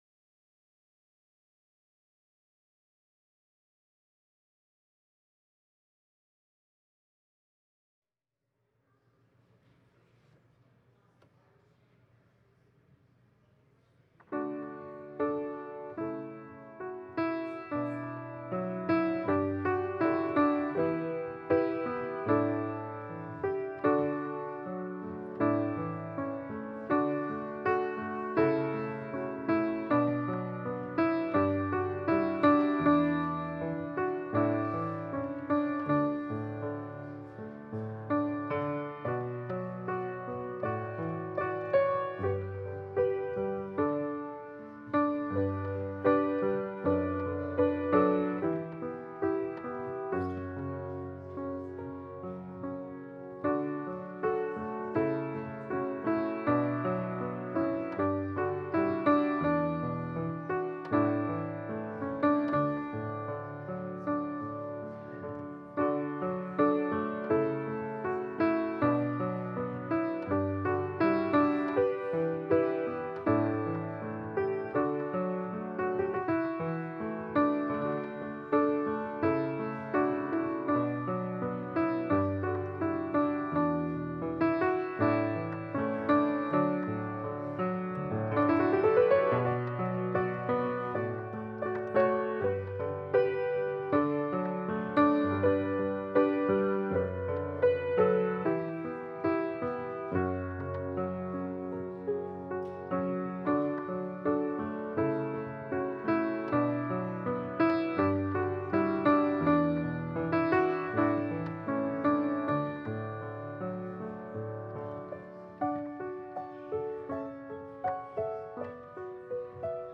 Psalm 114 Service Type: Sunday Service Scriptures and sermon from St. John’s Presbyterian Church on Sunday